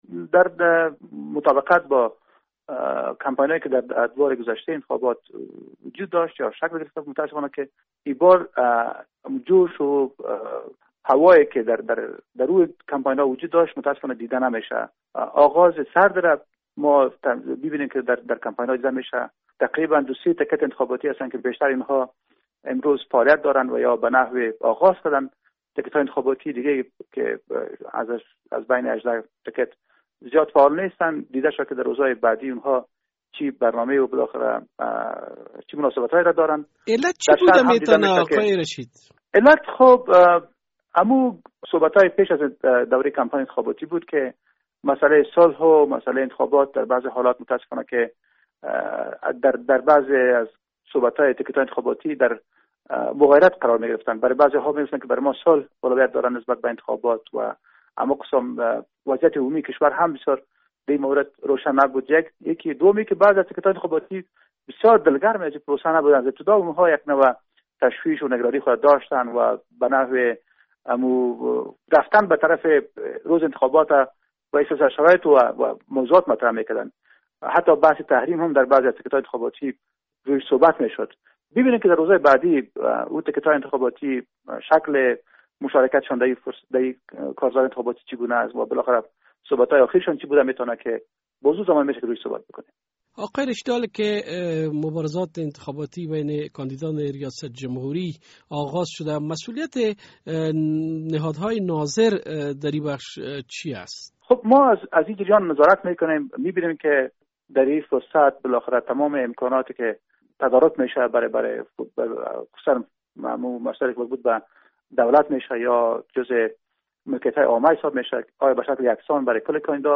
مصاحبۀ